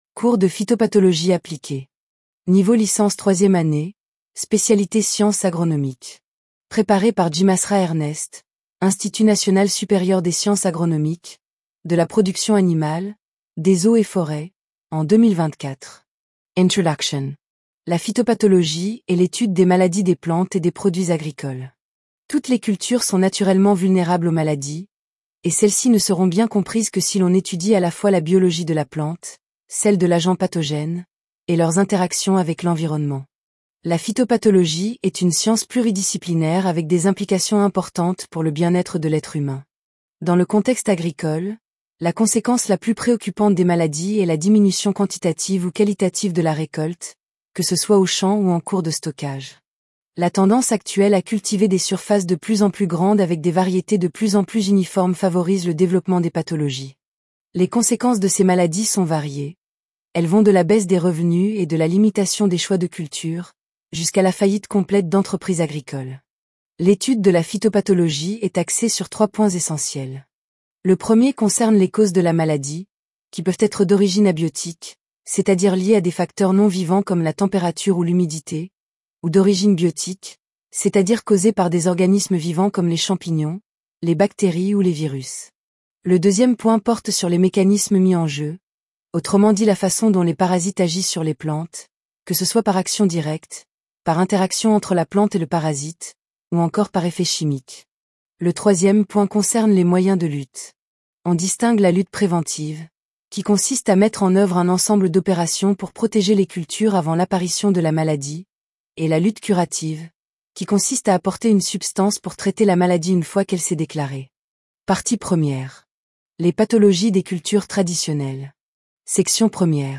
Phytopath_Appliquee_L3_TTS.mp3